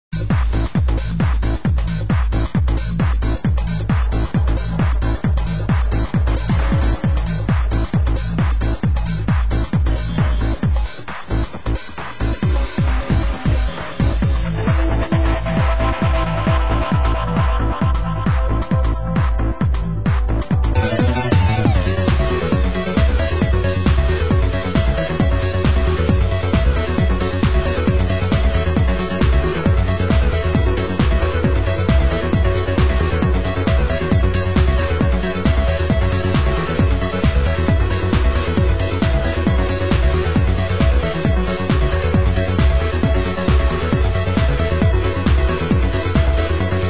Genre: UK House